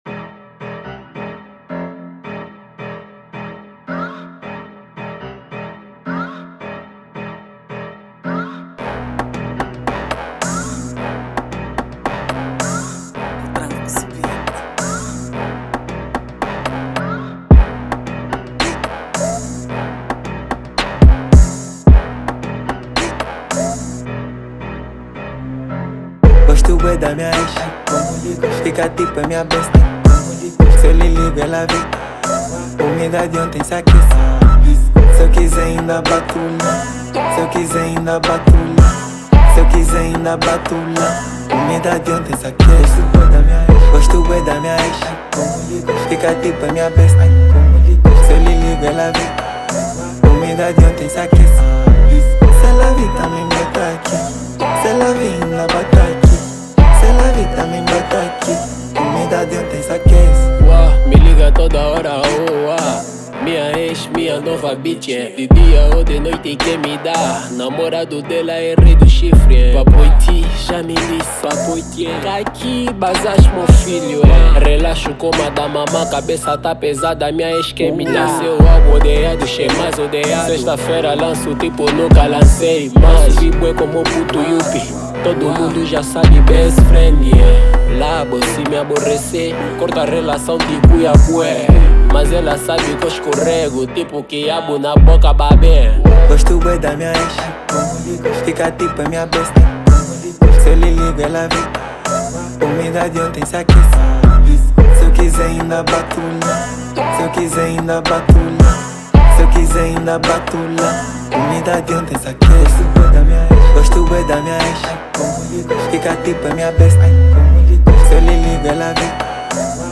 Categoria: Rap